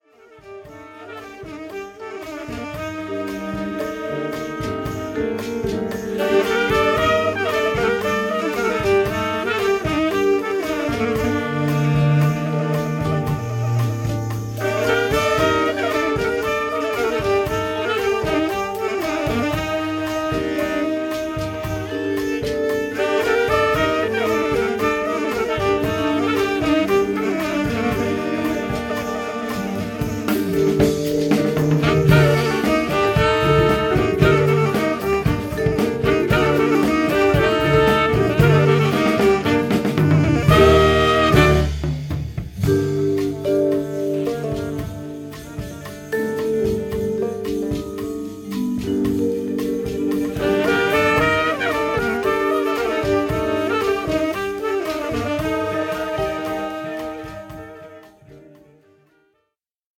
JAZZ / JAZZ FUNK / FUSION
エチオピアの音楽にジャズやラテン音楽などを融合させた「エチオ・ジャズ」